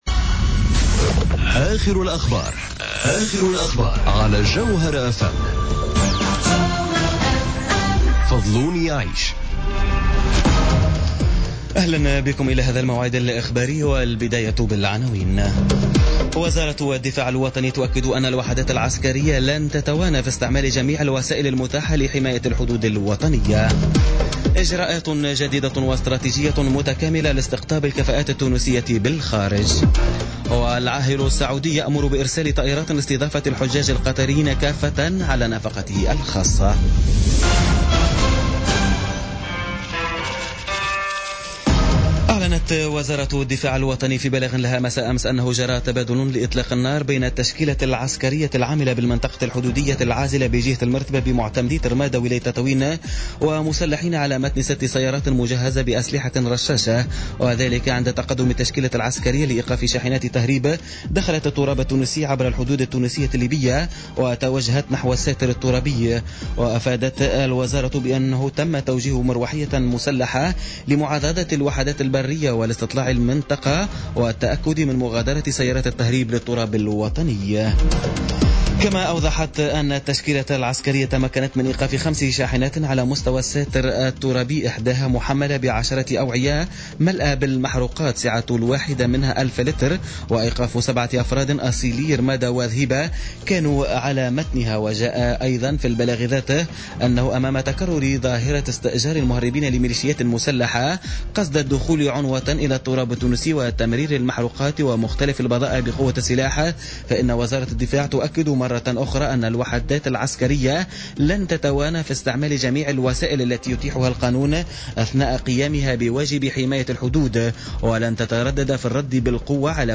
نشرة أخبار منتصف الليل ليوم الخميس 17 أوت 2017